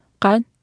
Speech synthesis Martha to computer or mobile phone